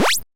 8位视频游戏的声音 " 计算弹球奖金的突发事件
描述：在为比分增加球末奖励时可以重复计数的声音。 使用SFXR创建。
Tag: 芯片 8位 复古 视频 噪音 游戏 副井 低保 商场 芯片音乐 抽取 视频游戏